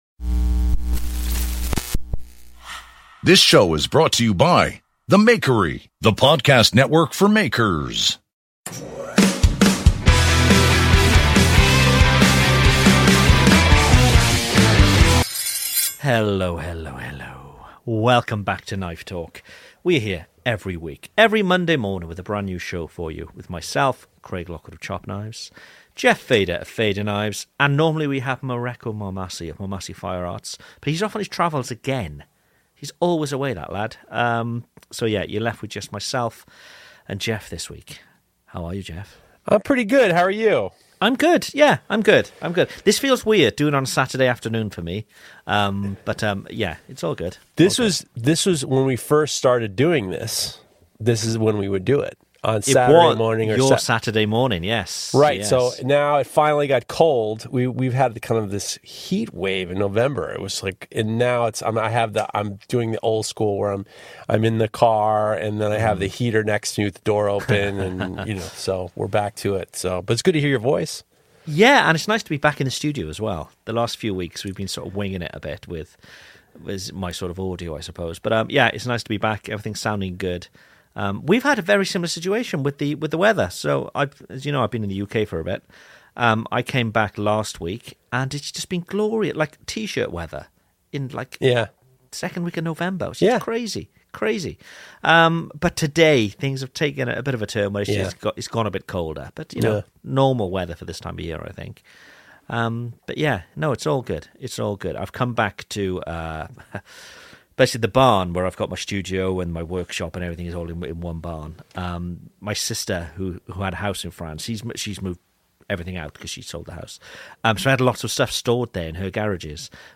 All 3 hosts are back and shooting the breeze. Topics include Damascus tiling, anvilles, the wisdom in trees and much much more.